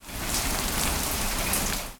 add rain noise & add rain splashes (WIP)
rain_4.ogg